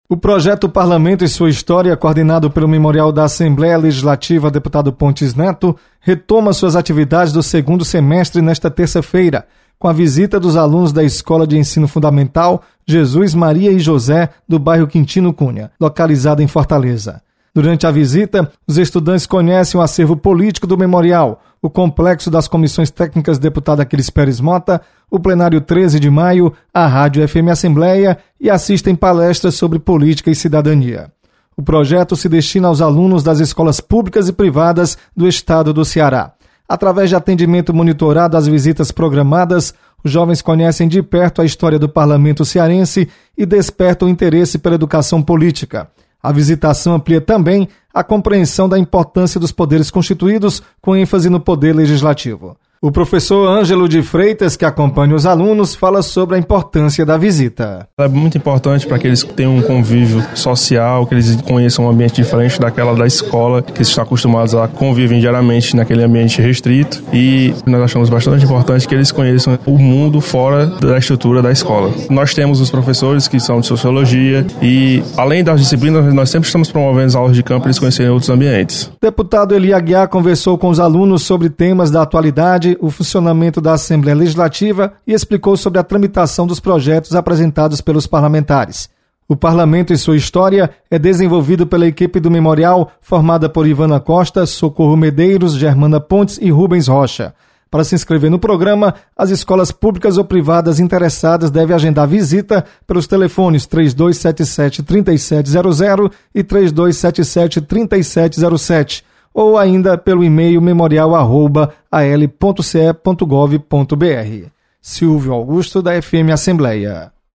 Projeto do Memorial da Assembleia retoma atividades. Repórter